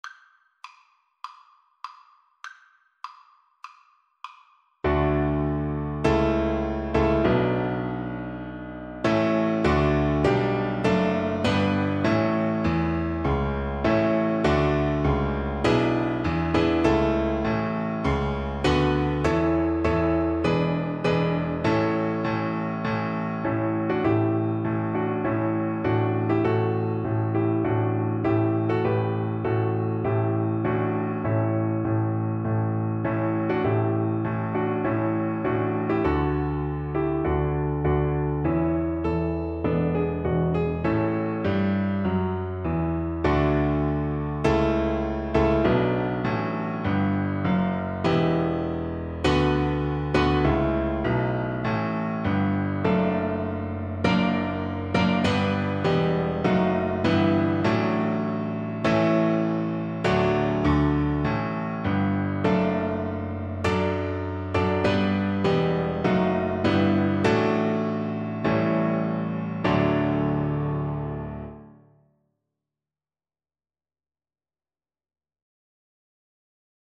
O Canada! (Canadian National Anthem) Free Sheet music for Violin
canadian_nat_anth_VLN_kar1.mp3